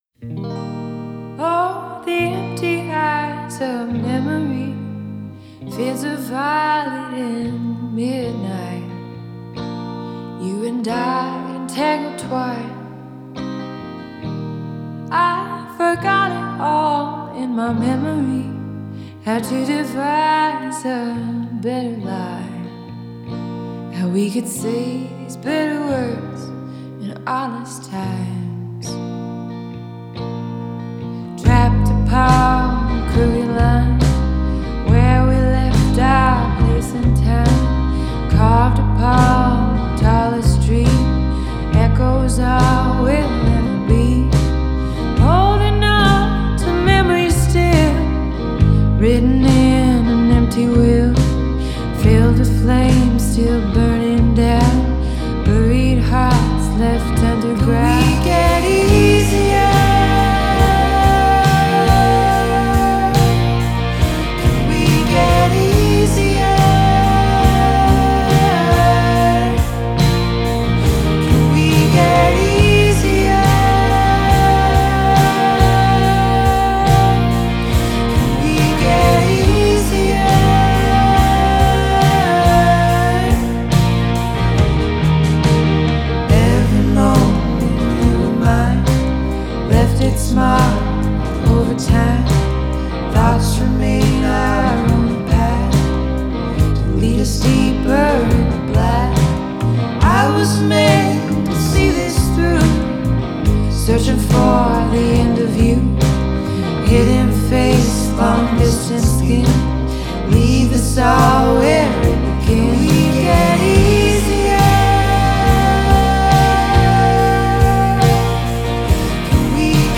que des morceaux davantage dans l'émotion